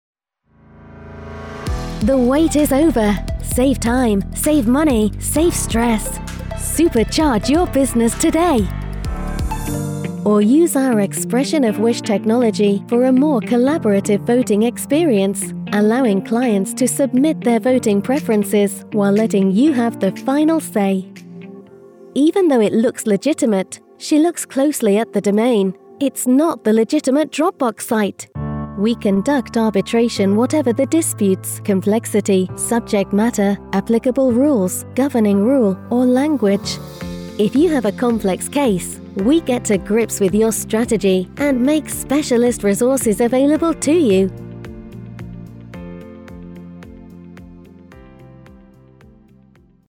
Authentic, sincere, assured & clear, an excellent choice for Commercials, Corporate, Explainer, E-Learning, IVR and On-Hold. I read with a neutral UK accent or regional accents including Lancashire, Manchester, Merseyside, Cheshire, with a wide variety of character voices too.
VOICE ACTOR DEMOS